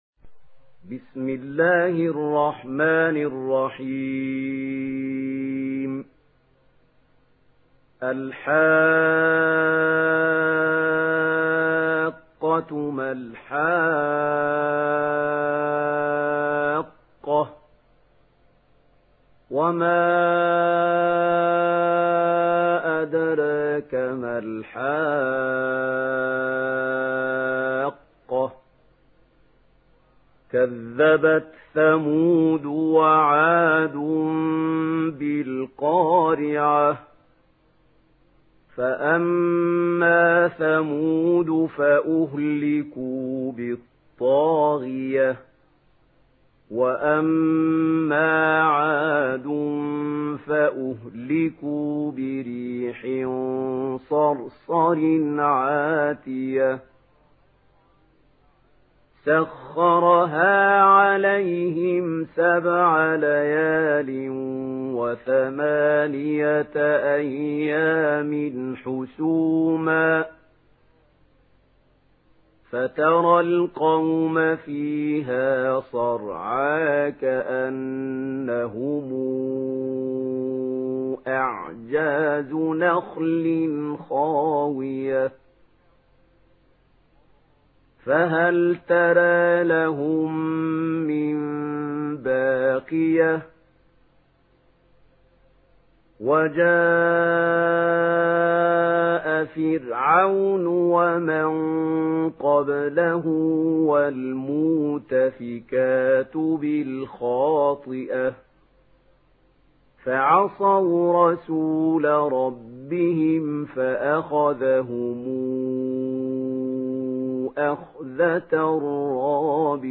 Surah Al-Haqqah MP3 in the Voice of Mahmoud Khalil Al-Hussary in Warsh Narration
Listen and download the full recitation in MP3 format via direct and fast links in multiple qualities to your mobile phone.